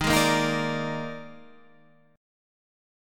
D#M7sus4 chord